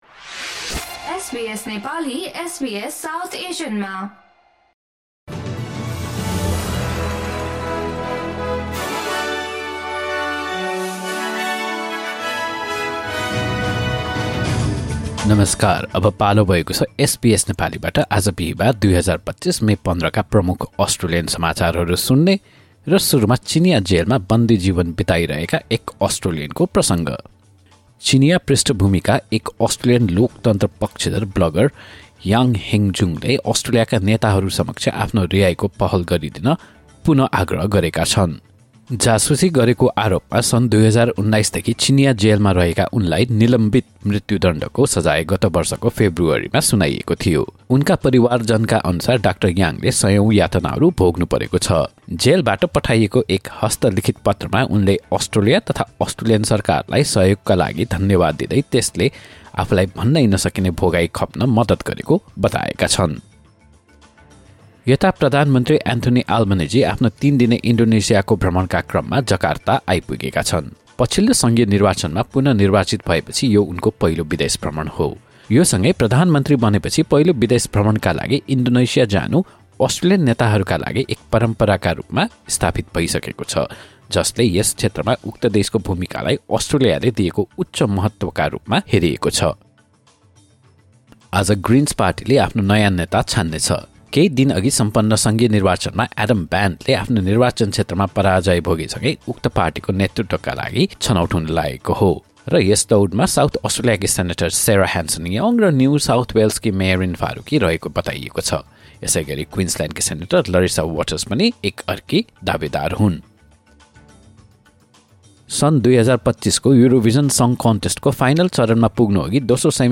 एसबीएस नेपाली प्रमुख अस्ट्रेलियन समाचार: बिहीवार, १५ मे २०२५